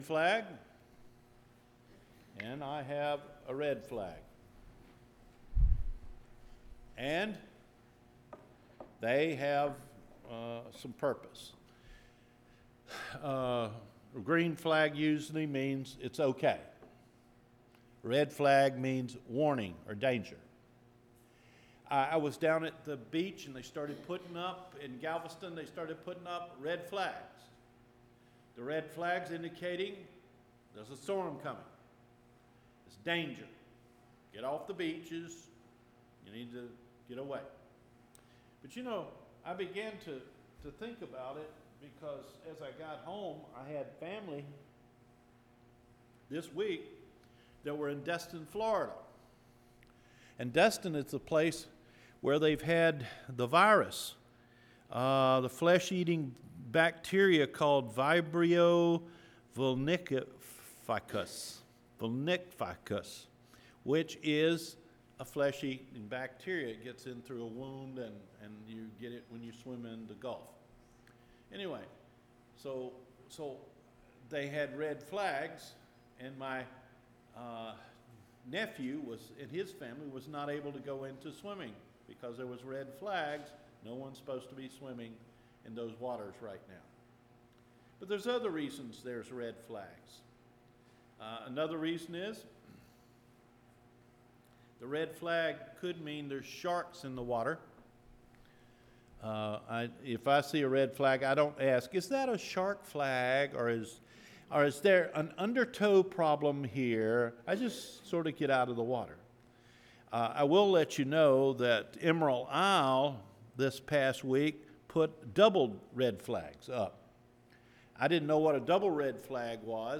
JESUS: THE GREEN FLAG – JULY 14 SERMON – Cedar Fork Baptist Church